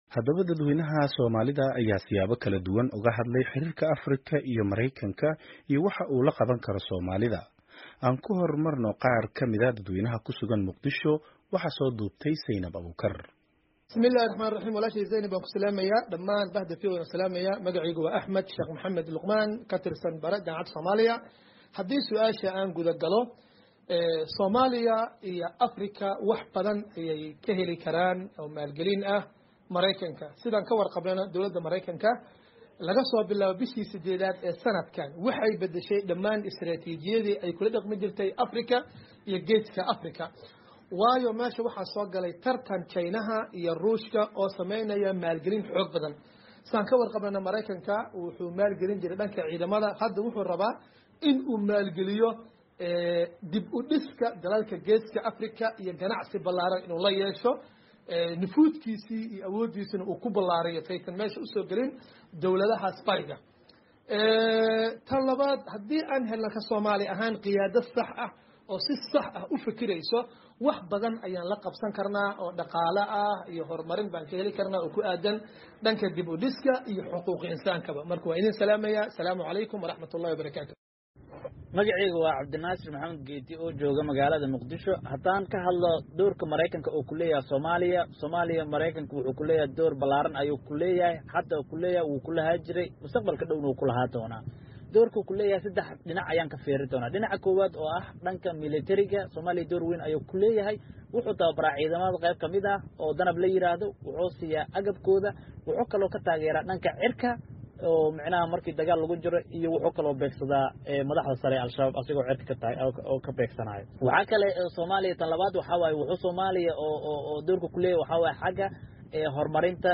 Haddaba dadweynaha Soomaalida ayaa siyaabo kala duwan uga hadlay xiriirka Afrika iyo Maraykanka, iyo waxa uu la qaban karo Soomaalida.